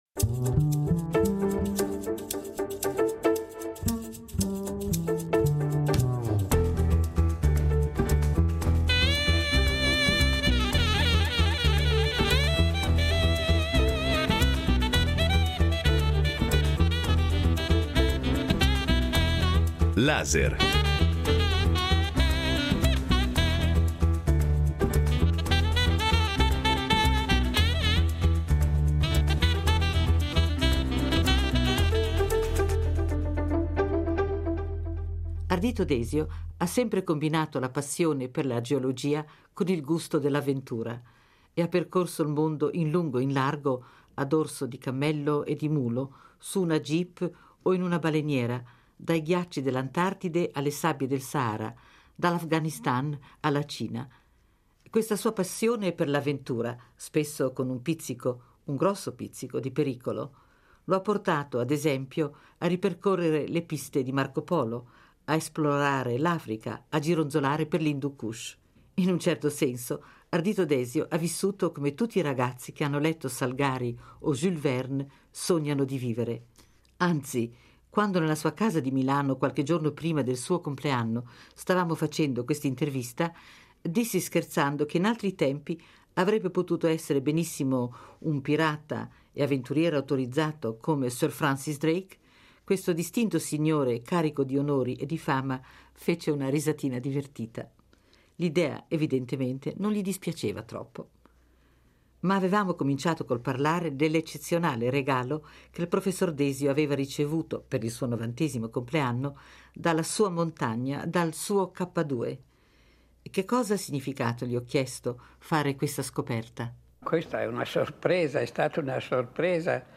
Nel documentario Desio parla della propria esperienza personale di grande viaggiatore e studioso.